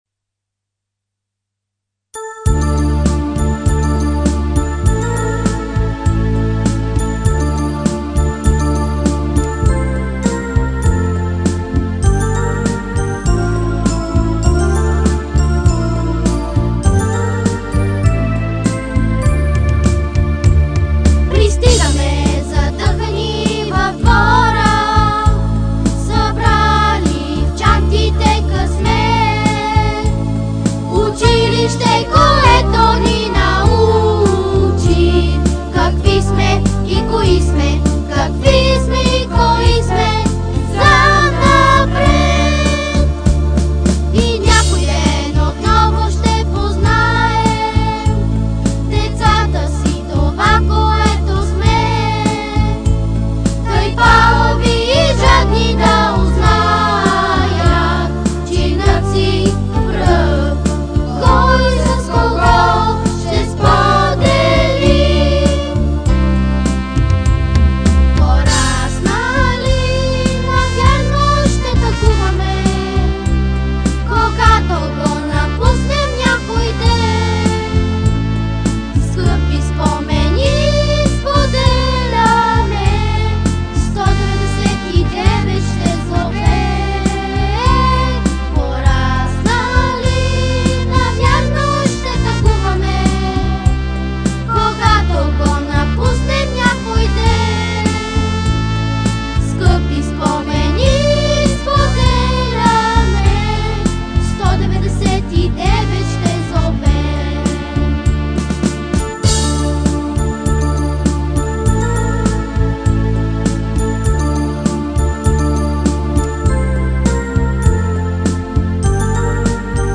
Чуйте химна на училището, в изпълнение на наши ученици
Студио, звукозапис, смесване
За поколенията: оригинална тоналност – ре минор